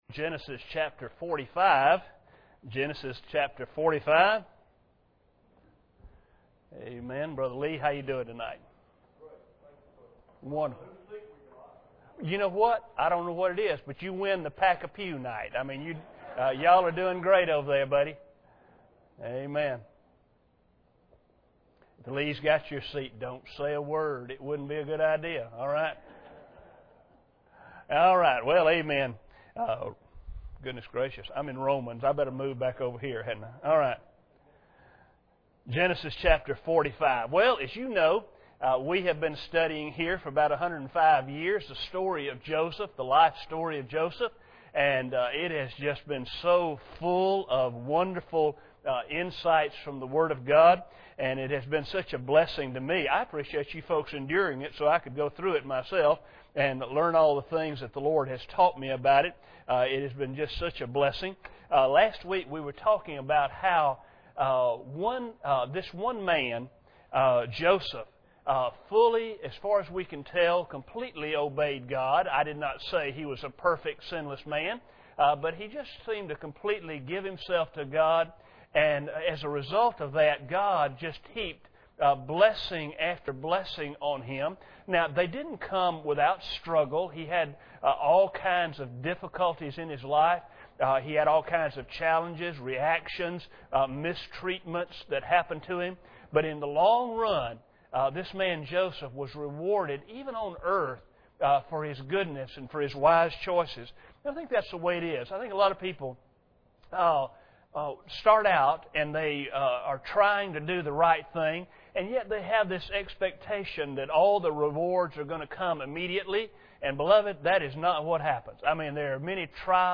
Genesis 45:4 Service Type: Sunday Evening Bible Text